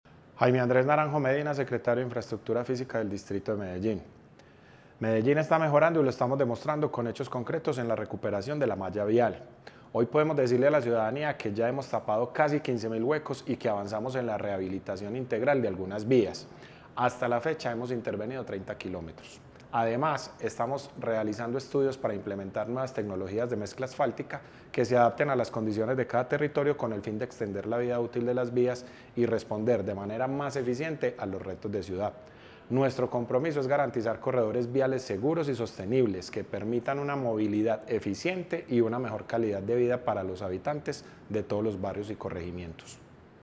Declaraciones del secretario de Infraestructura Física, Jaime Andrés Naranjo Medina
Declaraciones-del-secretario-de-Infraestructura-Fisica-Jaime-Andres-Naranjo-Medina.mp3